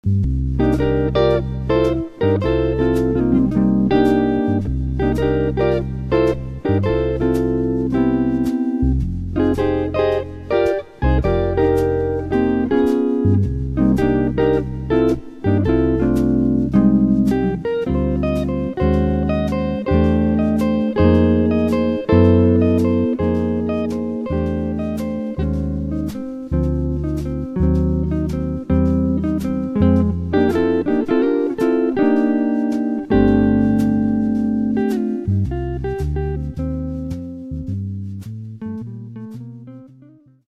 J A Z Z   L O O P I N G